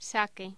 Locución: Sake
voz
Sonidos: Voz humana